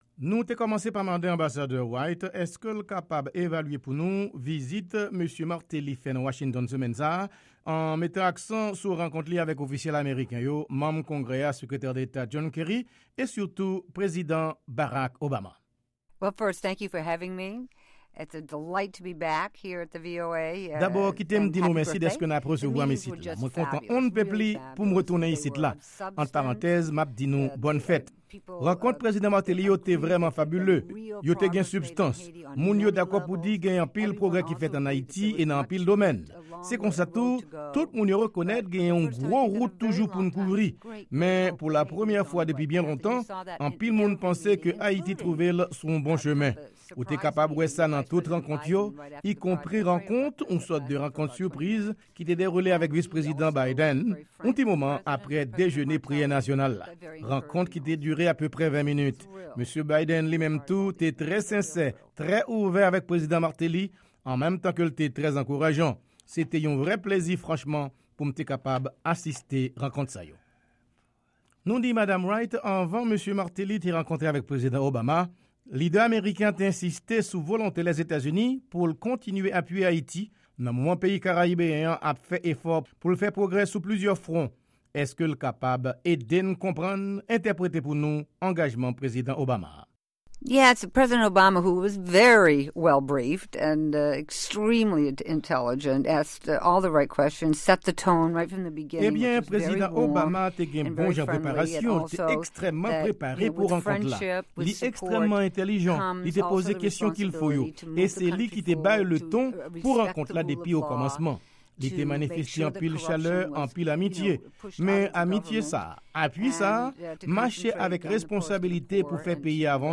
Entèvyou Ambasadè Pamela White avèk Sèvis Kreyòl VOA - 7 Fevriye 2014